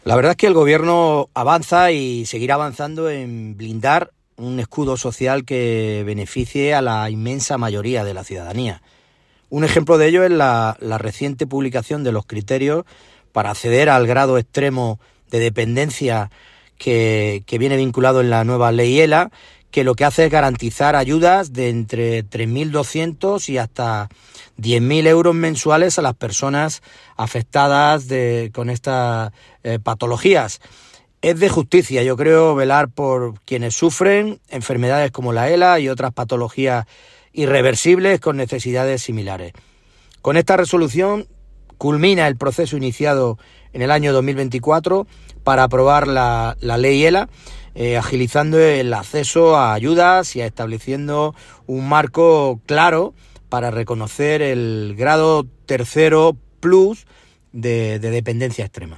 Corte-de-voz-Emilio-Saez-El-Gobierno-de-Espana-avanza-en-blindar-un-escudo-social-con-avances-en-LEY-ELA.mp3